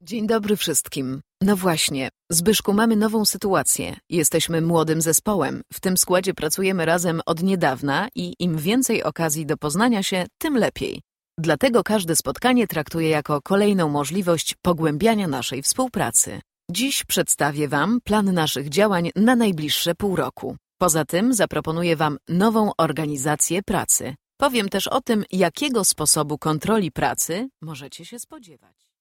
Klicken Sie sich hier durch unsere Auswahl an polnischen Muttersprachlern:
von Top-Profis aus Radio und Fernsehen eingesprochen.